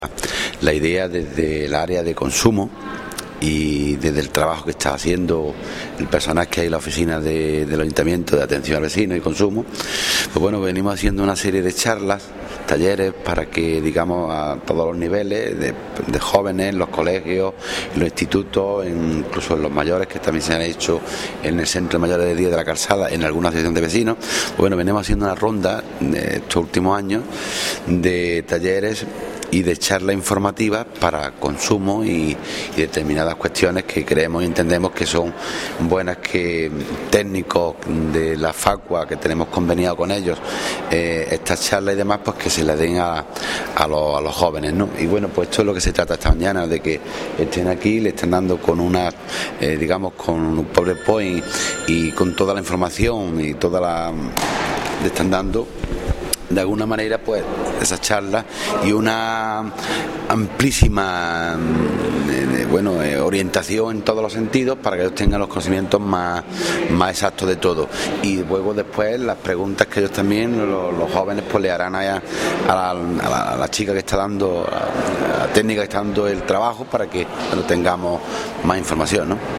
Cortes de voz
Audio: concejal de Consumo   1243.67 kb  Formato:  mp3